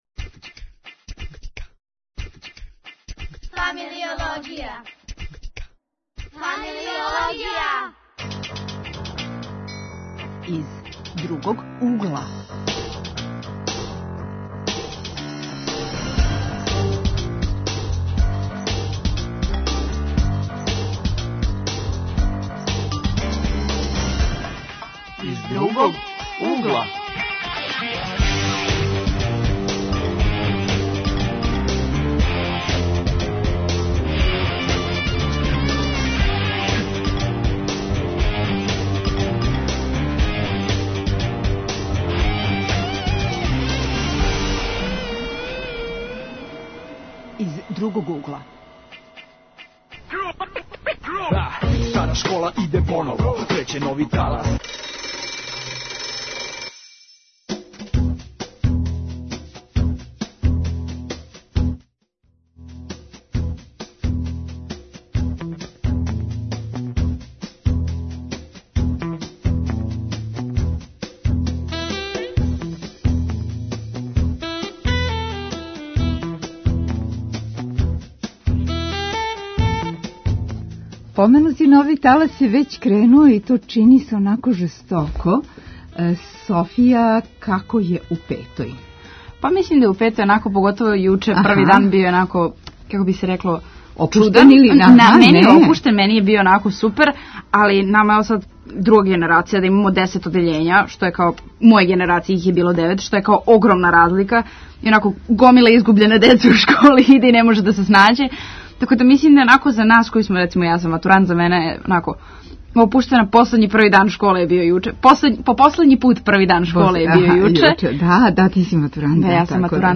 Гости: средњошколци.